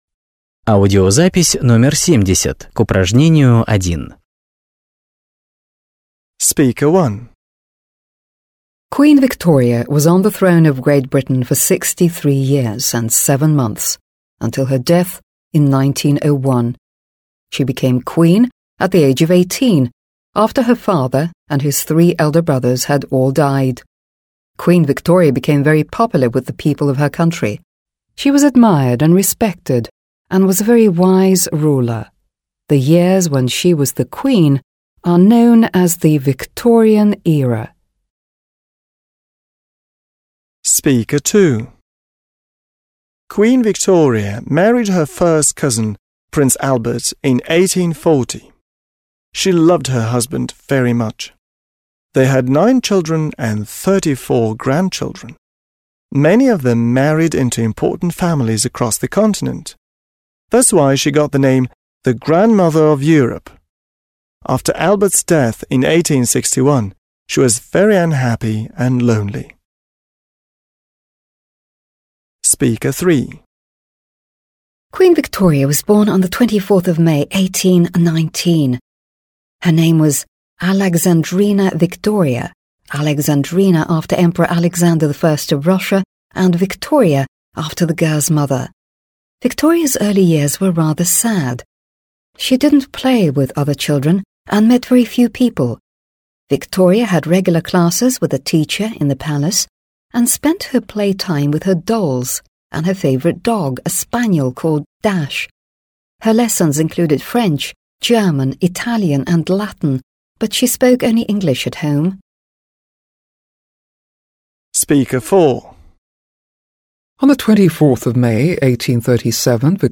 1. Послушайте, что разные люди говорят о королеве Виктории, (70), и сопоставьте говорящих (1-5) с утверждениями (a-f).